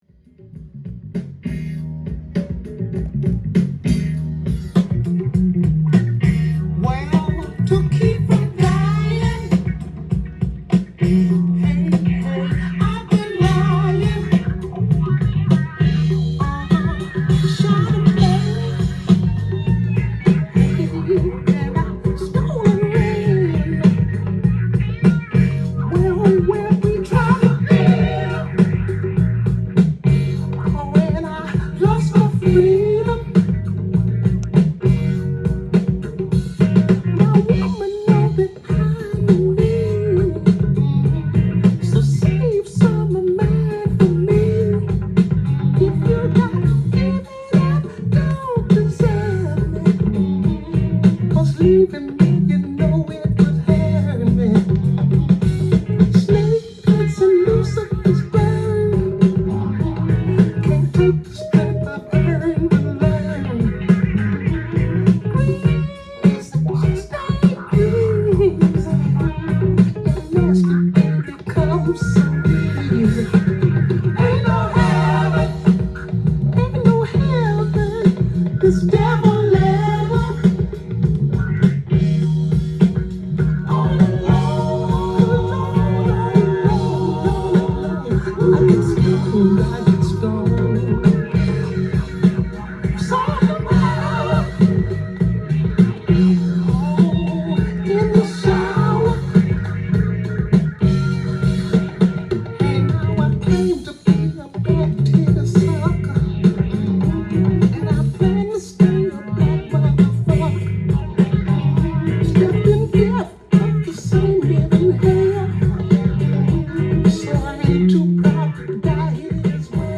ジャンル：FUSION
店頭で録音した音源の為、多少の外部音や音質の悪さはございますが、サンプルとしてご視聴ください。
粘着質の渋いソウルファンク